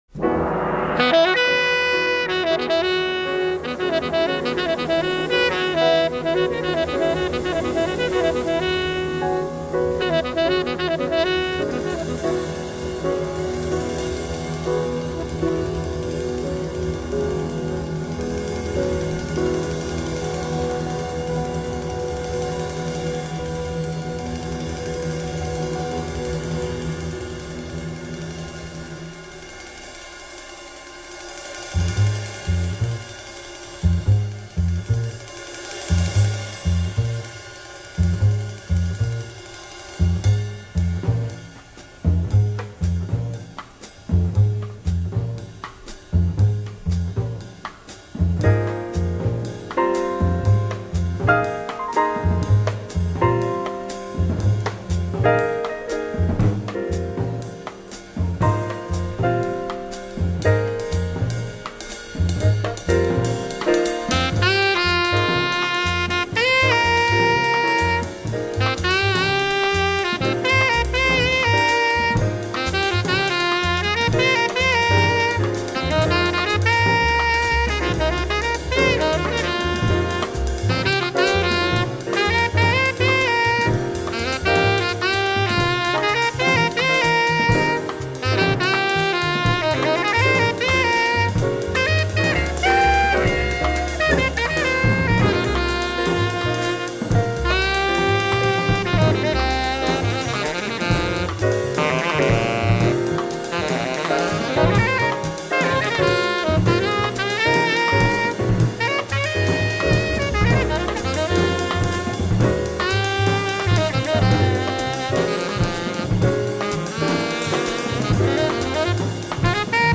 double bass
tenor sax
The quartet always comes back to the core though.